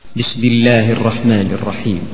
" استبدل صوت الموسيقى عند تشغيل الويندوز بـ ( البسملة ) "
مقطع البسمله ضغط هنا
BesmALLAH.WAV